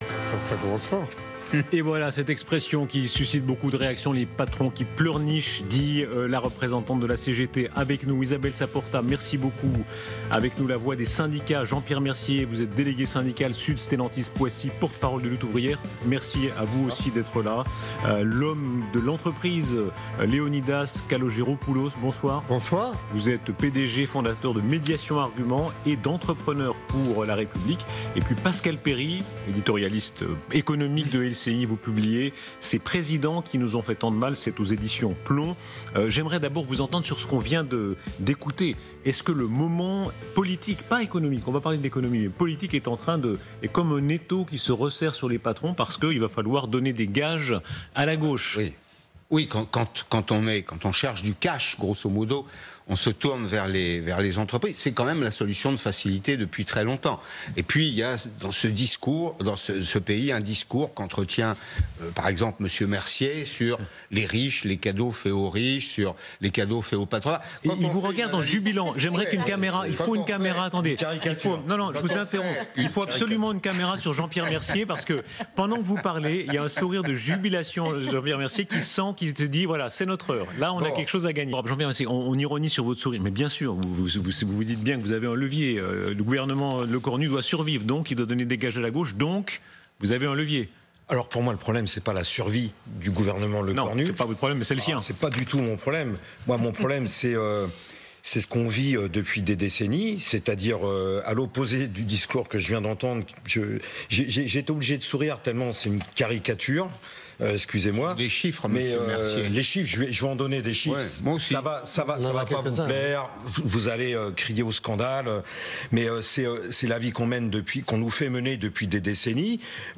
Débat LCI sur l'imposition du patronat